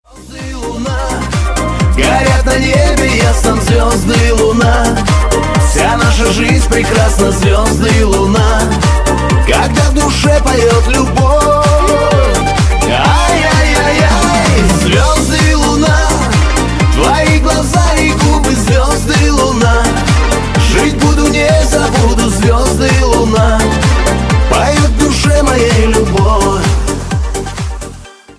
романтичные
русский шансон